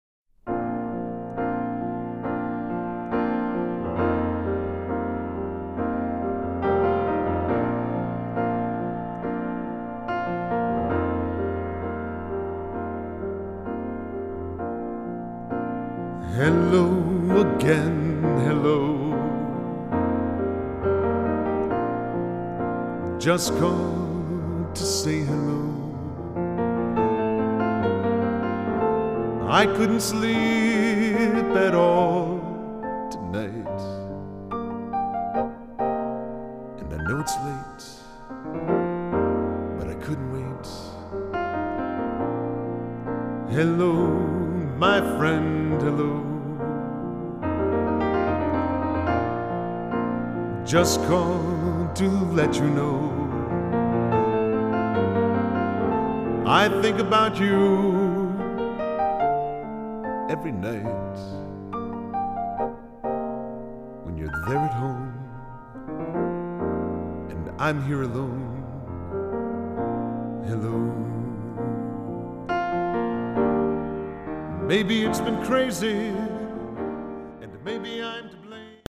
piano/vocal